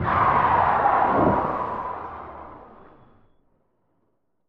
skidin1.ogg